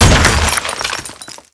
pd_stones1.wav